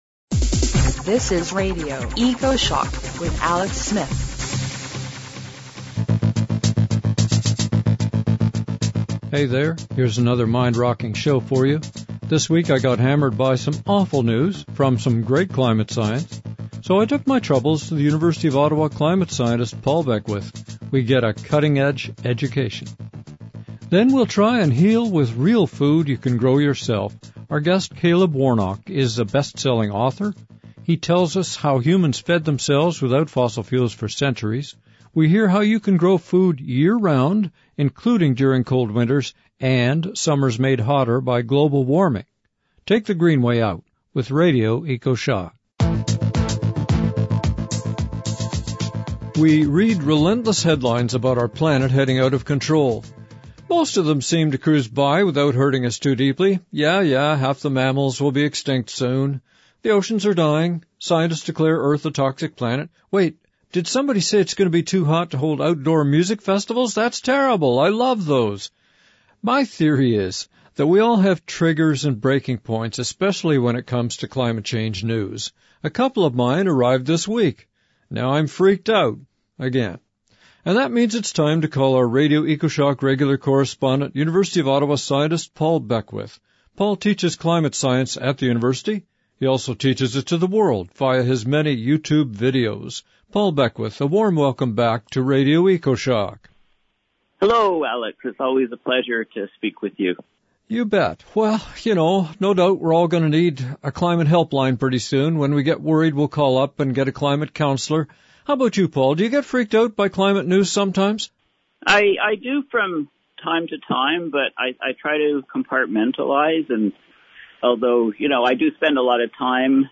Hey there, here is another mind-rocking show for you.